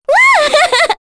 Kirze-Vox_Happy5_kr.wav